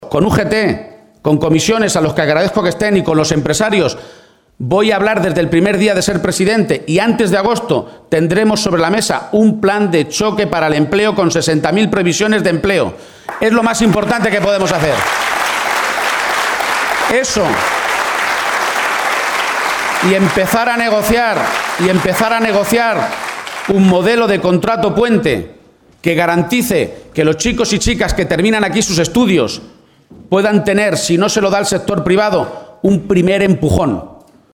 Asume cuatro compromisos firmes en su primer acto de campaña electoral, celebrado en Guadalajara